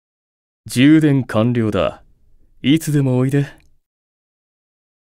Notification Audio Files
Lucifer_AP_Notification_Voice.ogg.mp3